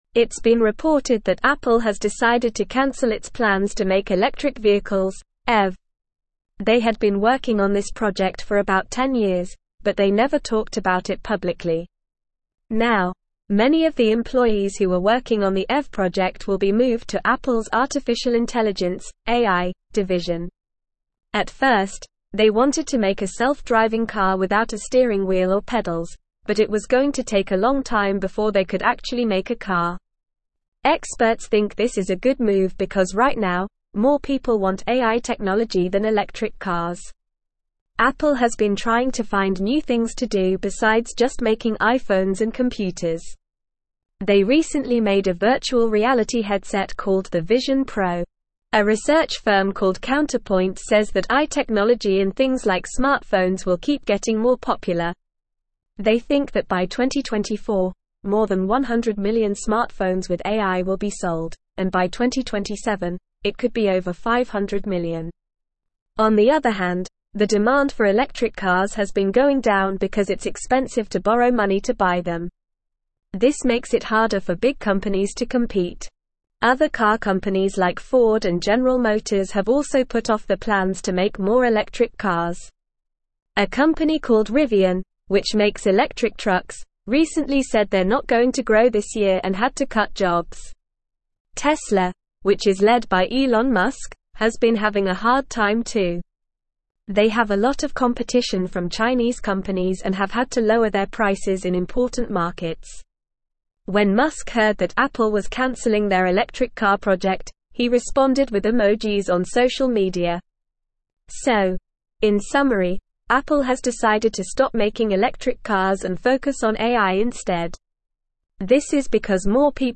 Normal
English-Newsroom-Upper-Intermediate-NORMAL-Reading-Apple-Abandons-Electric-Vehicle-Plans-Shifts-Focus-to-AI.mp3